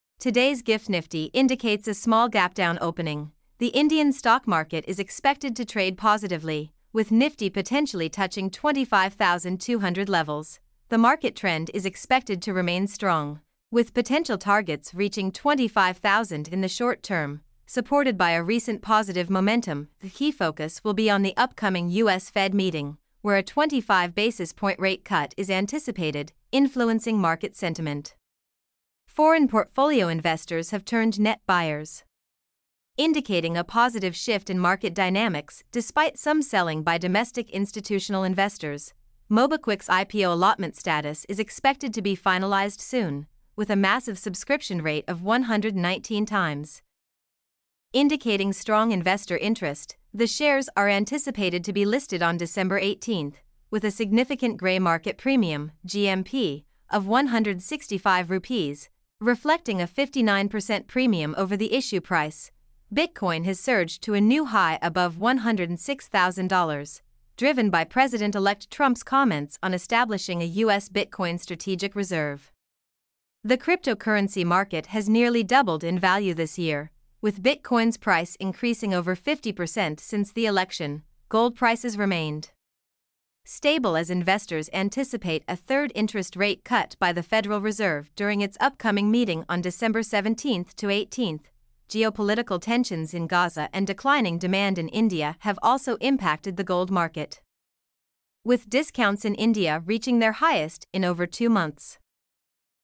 mp3-output-ttsfreedotcom-34.mp3